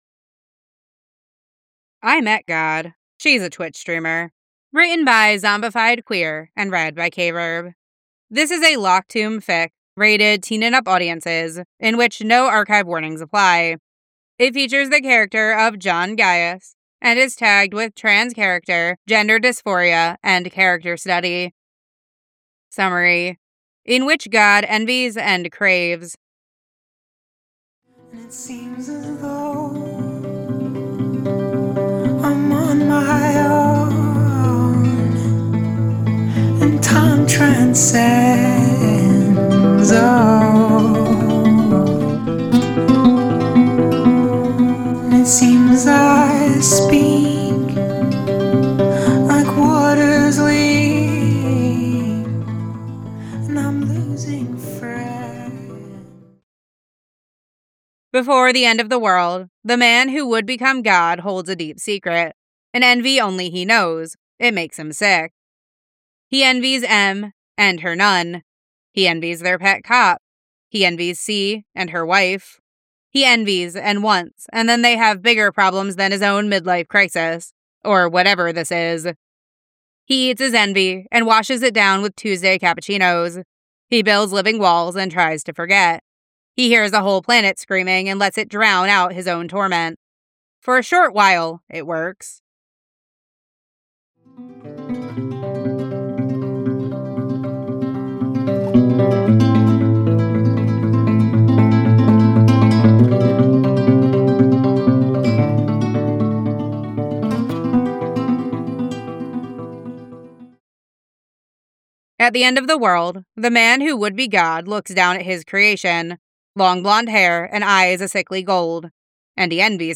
Alternate Music Version [Melancholy Vibes]: